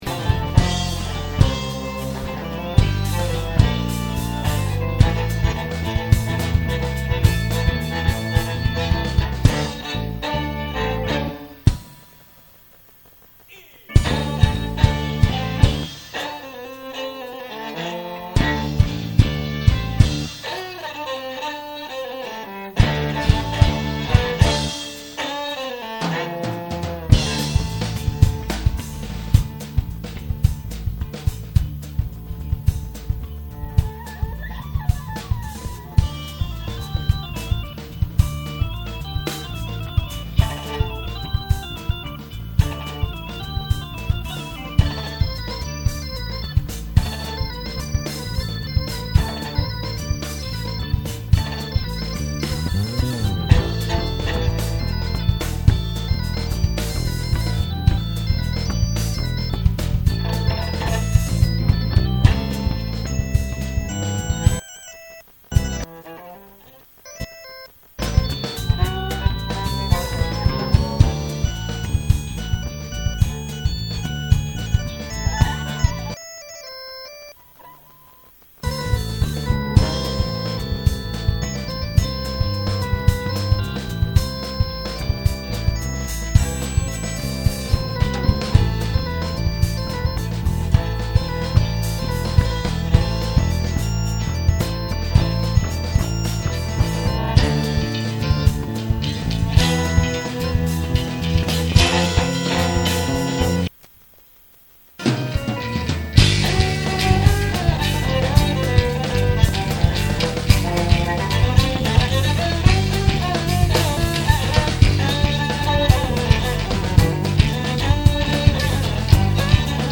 Концерт в "Орландине", 09.07.2007